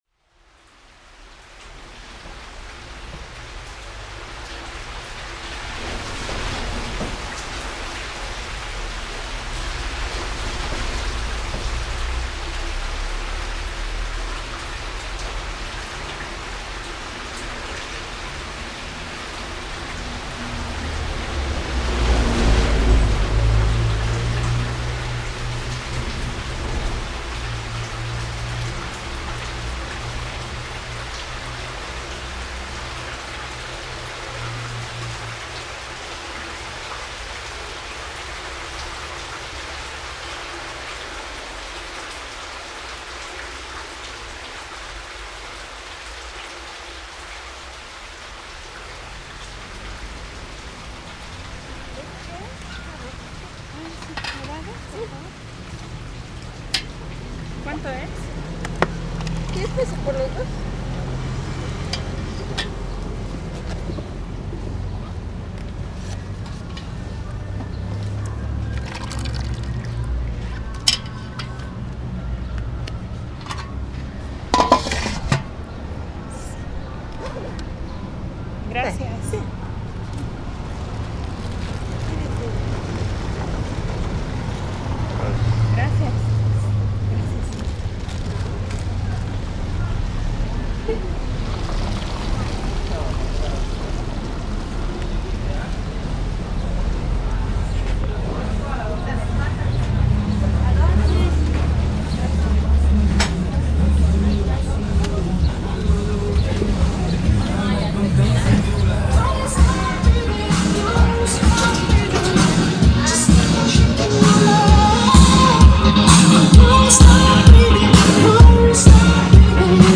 Paisaje Sonoro de Comitán de Domínguez
Este es un recorrido por Comitán de Domínguez, antes conocido como Balún Canan, ubicado en la región fronteriza del estado de Chiapas. Estos sonidos registrados en el mercado municipal, central de abastos, la pila, panteón municipal, parque central, Centro Cultural Rosario Castellanos, panadería, tlapalería y diversas calles, reflejan parte de la vida social y cultural, la cual ha sido construida a lo largo de los siglos.
Equipo: Grabadora Sony ICD-UX80 Stereo, Micrófono de construcción casera ( más info ) Fecha: 2010-08-31 22:03:00 Regresar al índice principal | Acerca de Archivosonoro